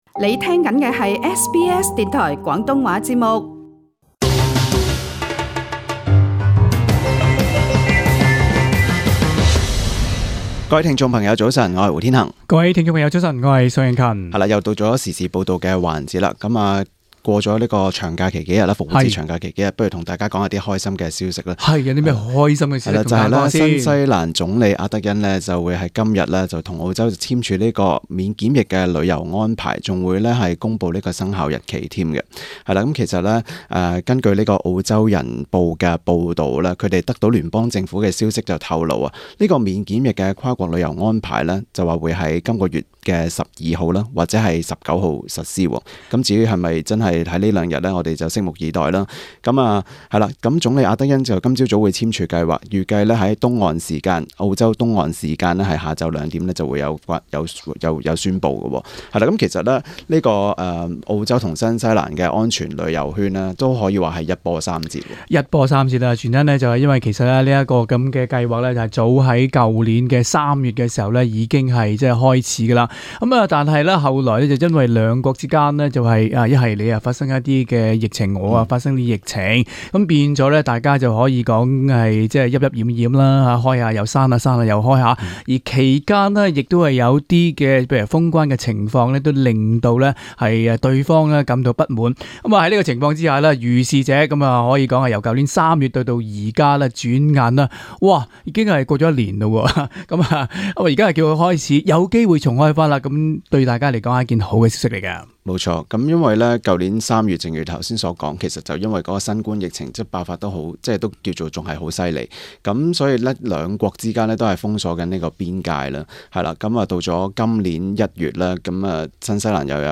他們都認為，值得高興的是，新西蘭政府認真考慮了開放邊境，有助新西蘭經濟復蘇等有利條件，詳情請收聽這節【時事報道】。